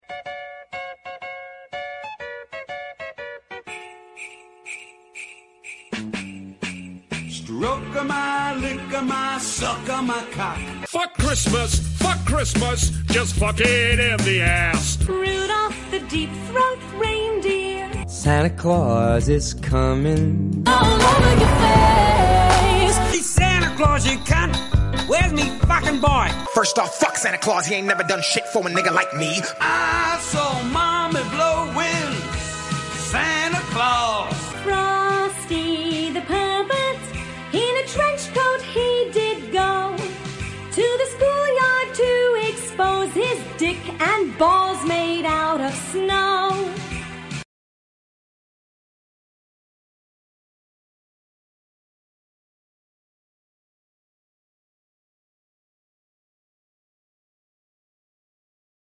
When you change the radio sound effects free download
When you change the radio station at the worst possible moment and accidentally create the most cursed song ever